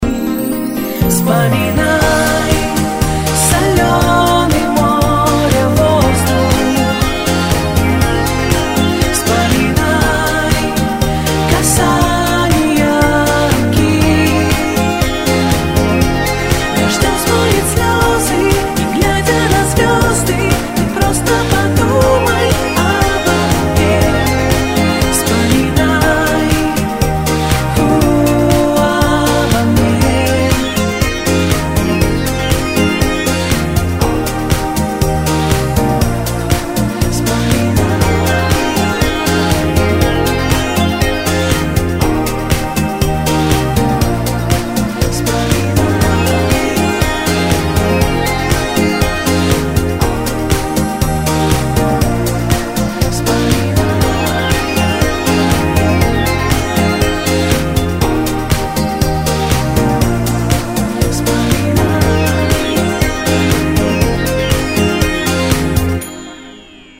Танцевальные рингтоны
Мужской голос
Поп